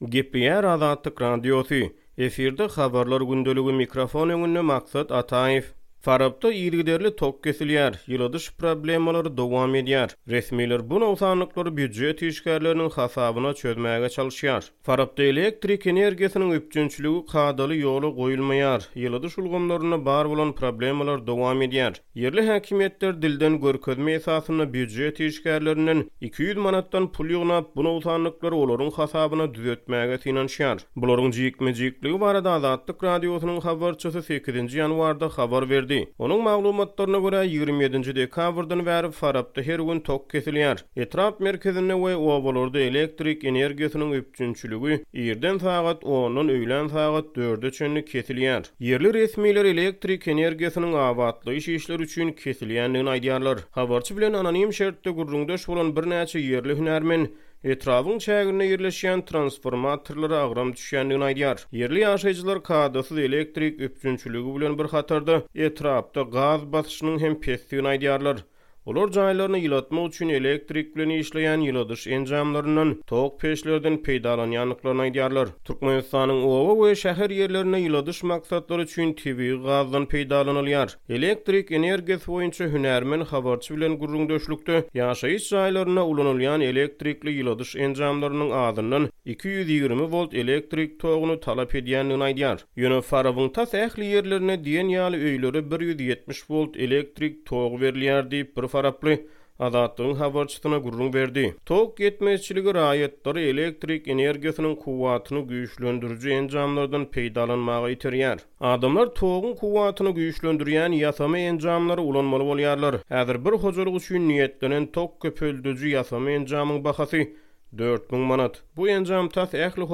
Ýerli häkimiýetler dilden görkezme esasynda, býujet işgärlerinden 200 manatdan pul ýygnap, bu nogsanlyklary olaryň hasabyna düzetmäge synanyşýar. Bularyň jikme-jikligi barada Azatlyk Radiosynyň habarçysy 8-nji ýanwarda habar berdi.